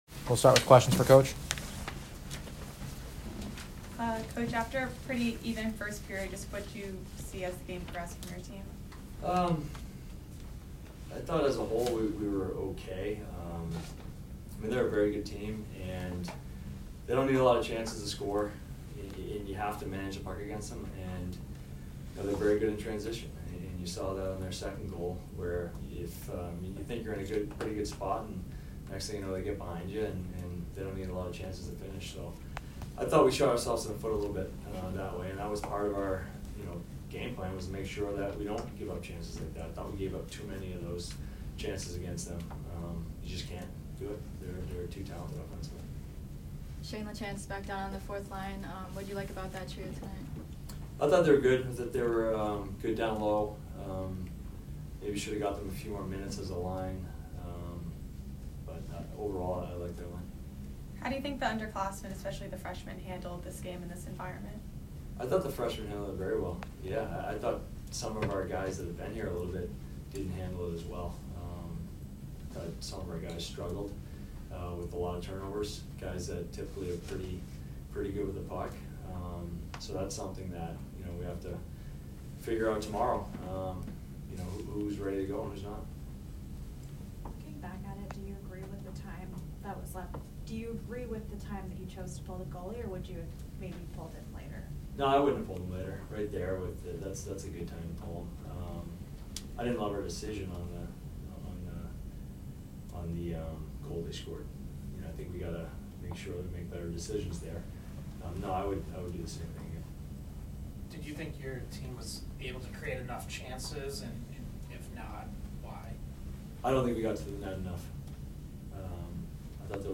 Boston College Postgame Interview